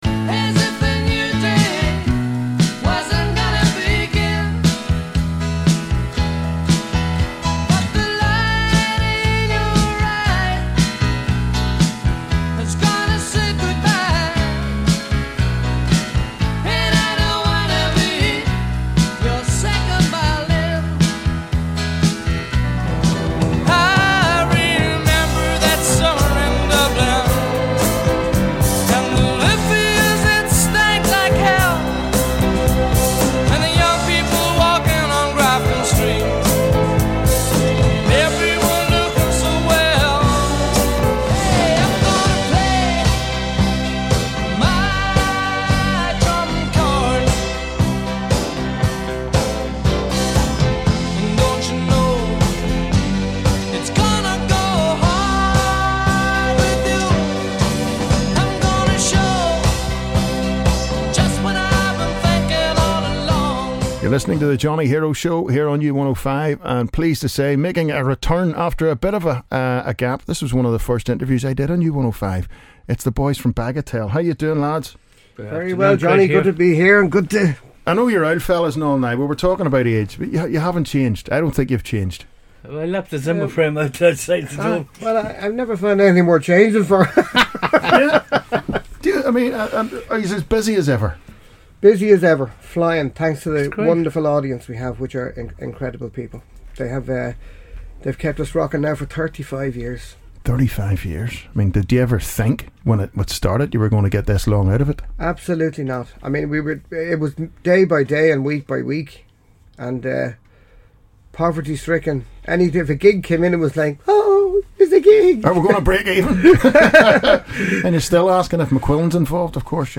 in conversation with Bagatelle!!